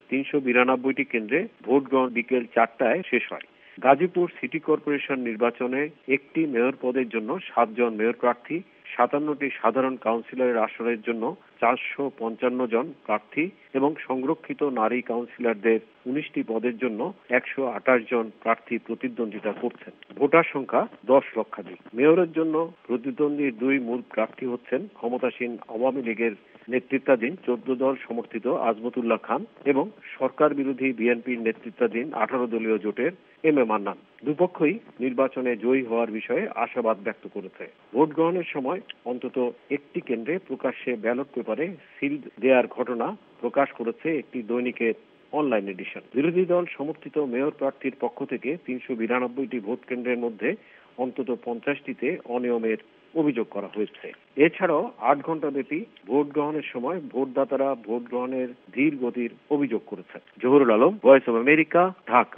আজ শনিবার কড়া নিরাপত্তার মধ্যে গাজিপুর সিটি কর্পোরেশনের নির্বাচন মোটামুটি শান্তিপুর্ণভাবেই সম্পন্ন হ’লো – জানাচ্ছেন ঢাকা থেকে আমাদের সংবাদদাতা